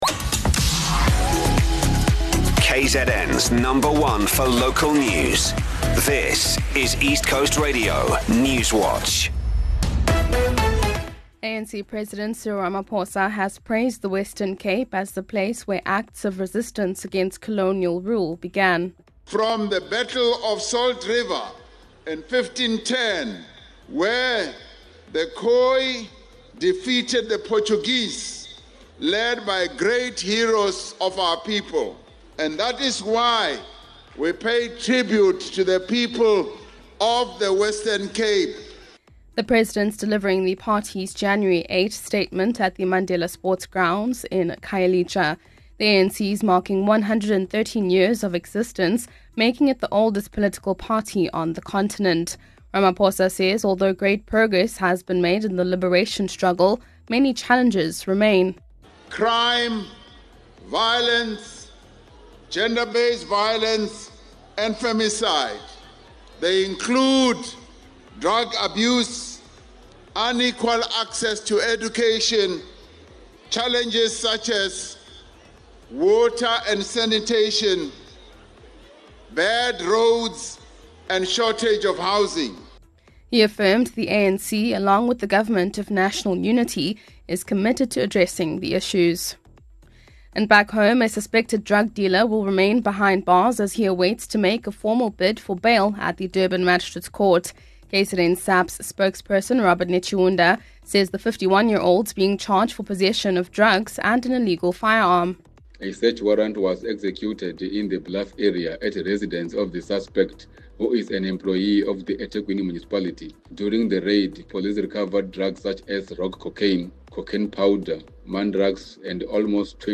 1 ECR Newswatch @ 13H00 3:21 Play Pause 2h ago 3:21 Play Pause Toista myöhemmin Toista myöhemmin Listat Tykkää Tykätty 3:21 Here’s your latest ECR Newswatch bulletin from the team at East Coast Radio.